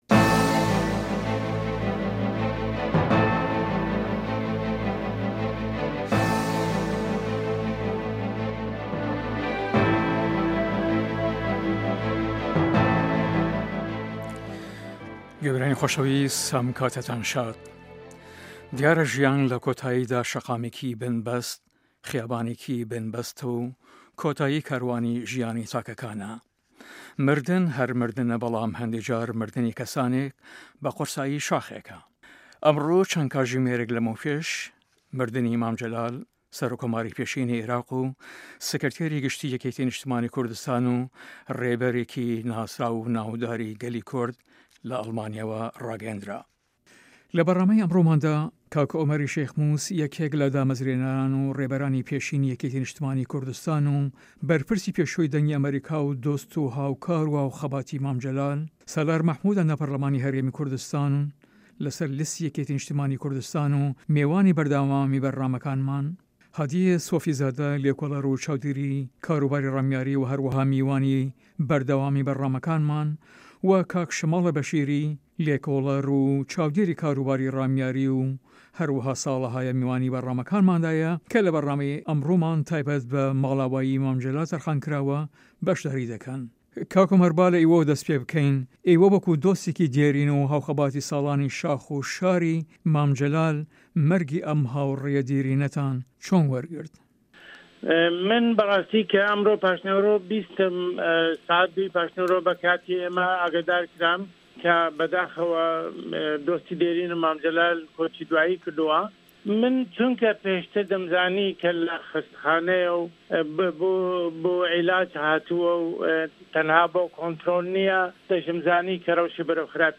Round Table.